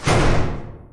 door-close-remote.wav